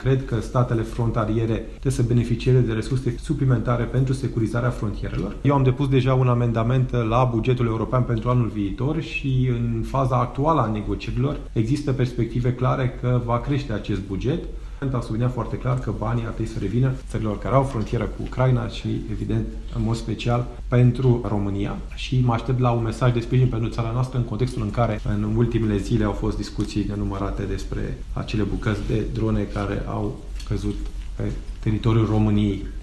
Europarlamentarul social-democrat Victor Negrescu a cerut, în plenul Parlamentului European reunit la Strasbourg, fonduri europene pentru protejarea graniţelor Uniunii şi acordarea de asistenţă şi ajutoare comunităţilor din zonele de frontieră, aflate în pericol din cauza războiului din Ucraina.